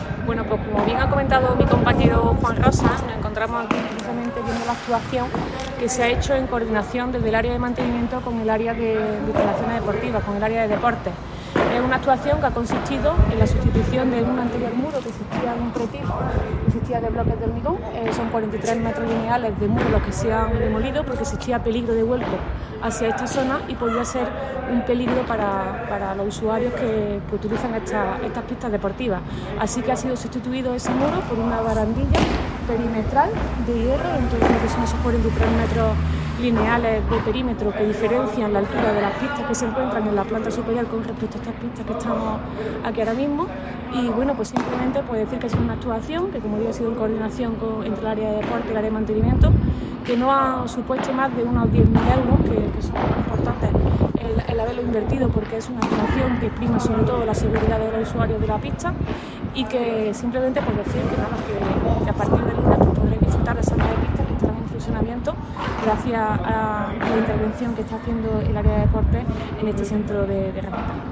El teniente de alcalde delegado de Deportes, Juan Rosas, y la teniente de alcalde de Obras y Mantenimiento, Teresa Molina, informan de las últimas actuaciones de mejora que se han llevado a cabo en el Centro Deportivo Momo González de La Quinta y que permitirá, desde el próximo lunes 13 de febrero, el alquiler de tres nuevas pistas que se unen a las seis anteriormente disponibles, por lo que ya quedan a disposición de los usuarios un total de 9.
Cortes de voz